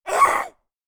femalezombie_attack_01.ogg